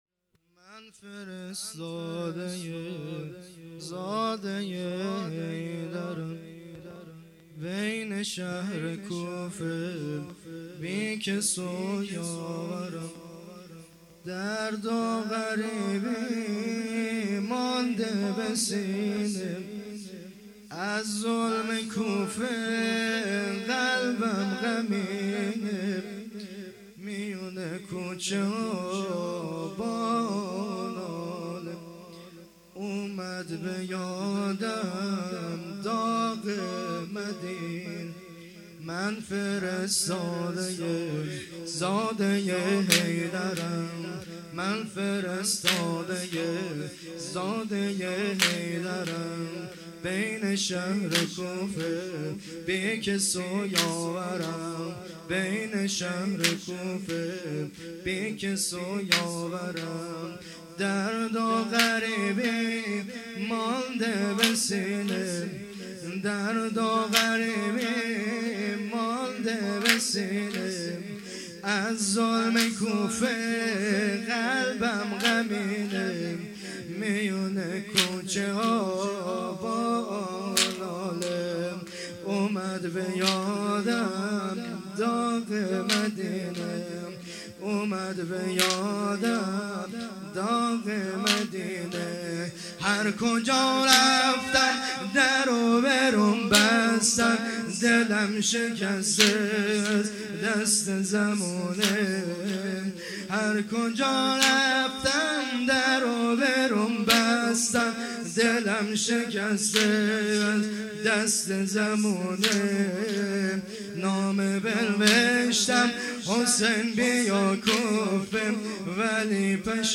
شب اول - دهه اول محرم 1404